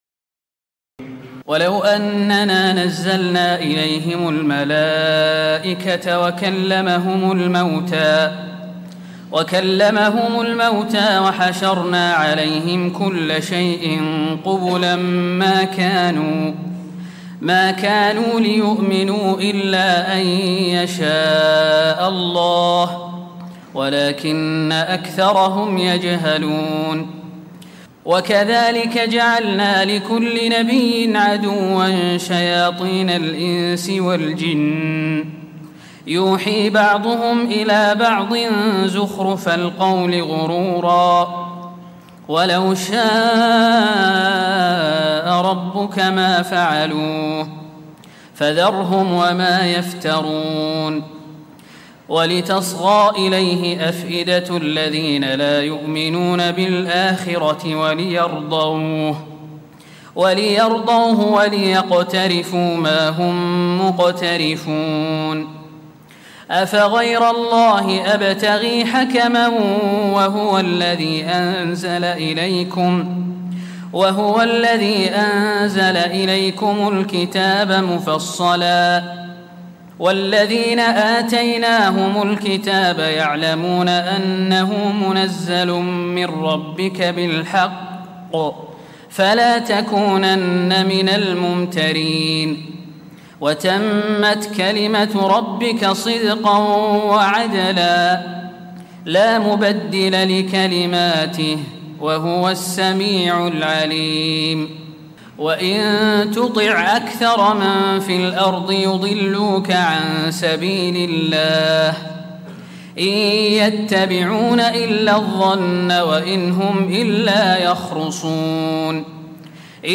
تراويح الليلة الثامنة رمضان 1436هـ من سورة الأنعام (111-165) Taraweeh 8 st night Ramadan 1436H from Surah Al-An’aam > تراويح الحرم النبوي عام 1436 🕌 > التراويح - تلاوات الحرمين